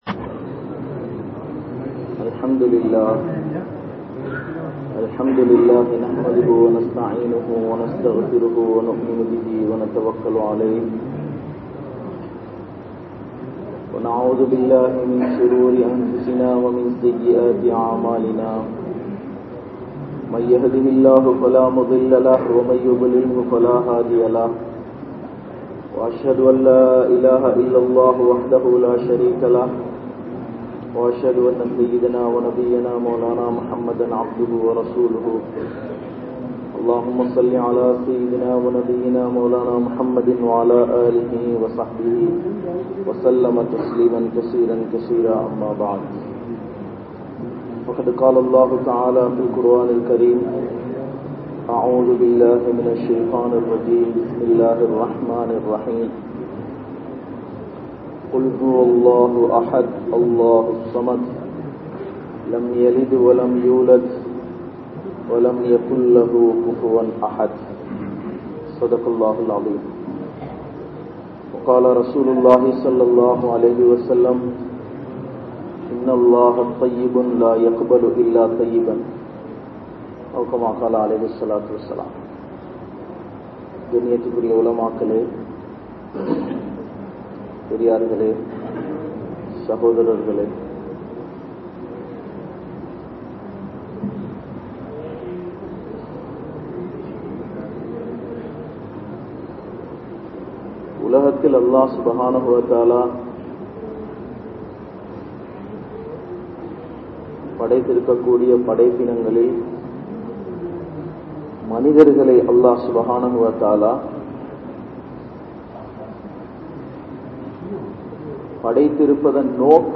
Allah`vai Payanthu Vaalungal (அல்லாஹ்வை பயந்து வாழுங்கள்) | Audio Bayans | All Ceylon Muslim Youth Community | Addalaichenai